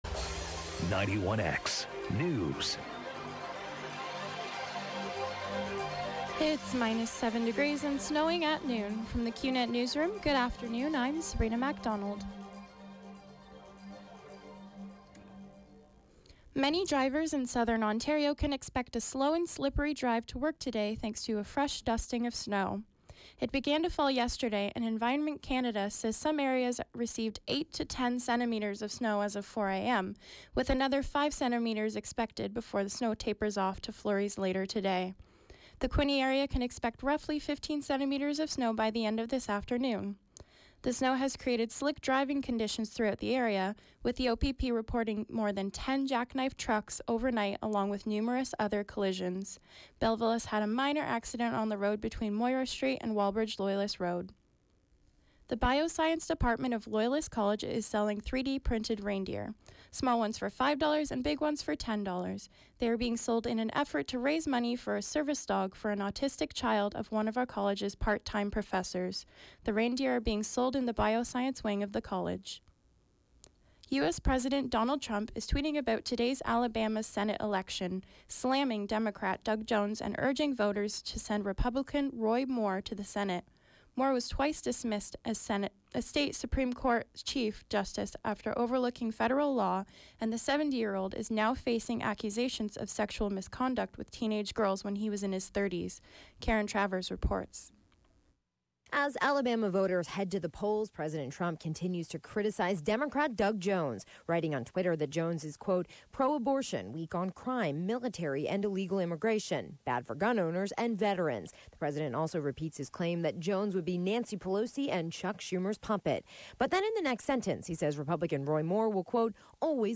91X Newscast: Tuesday, Dec. 12, 2017, noon.